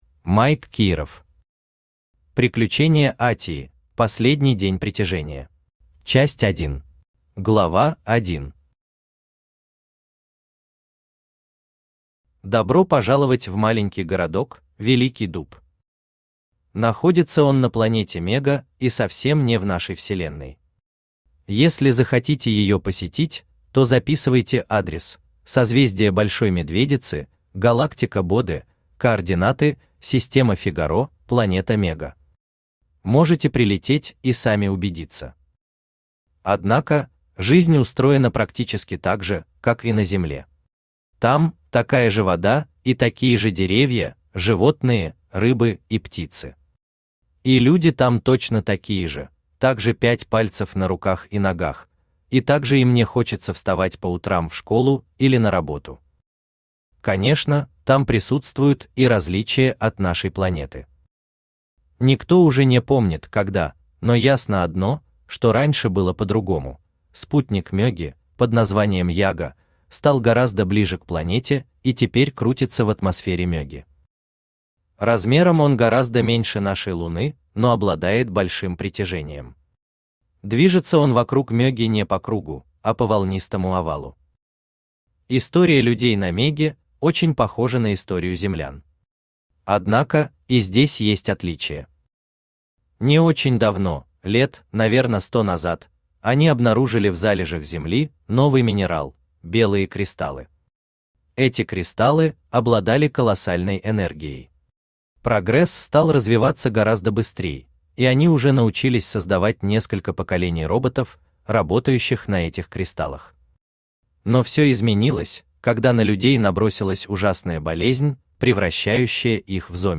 Аудиокнига Приключения Атии: Последний день притяжения | Библиотека аудиокниг
Aудиокнига Приключения Атии: Последний день притяжения Автор Майк Киров Читает аудиокнигу Авточтец ЛитРес.